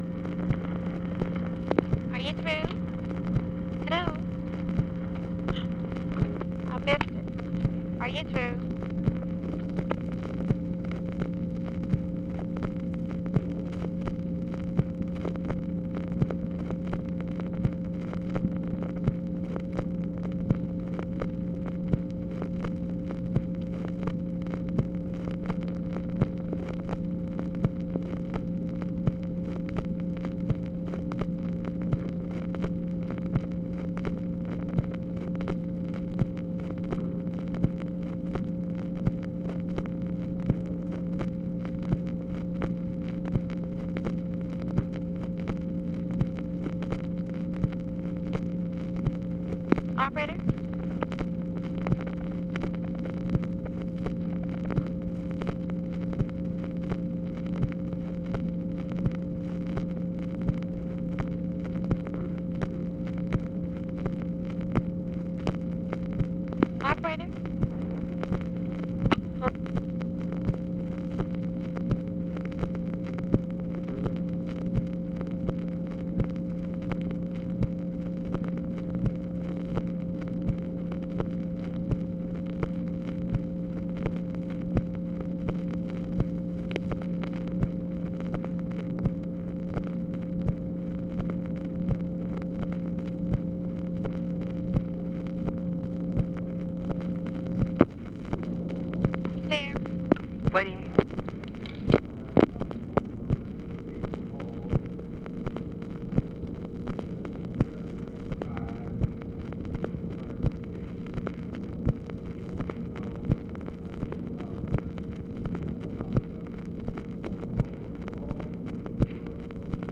SERIES OF "WAITING" AND OTHER OPERATOR VOICES; NO MEANINGFUL CONVERSATION
Conversation with TELEPHONE OPERATOR and TELEPHONE OPERATOR, March 25, 1965